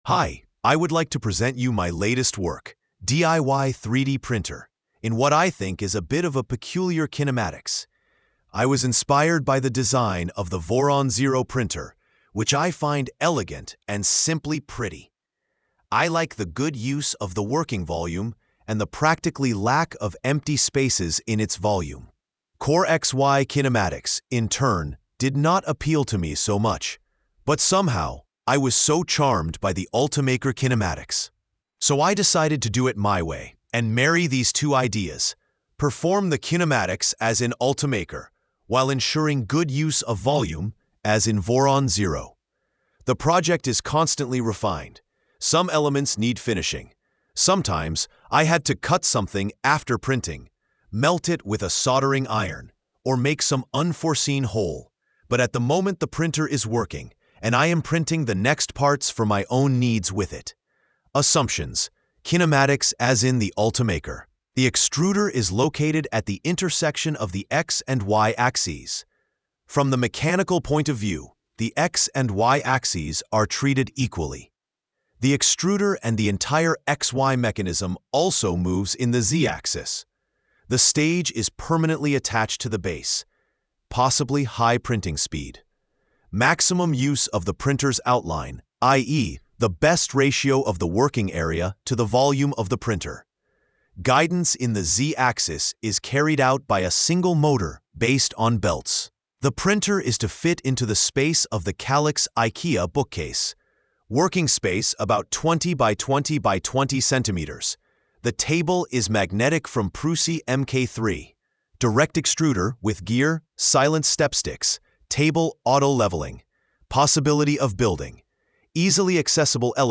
📢 Listen (AI):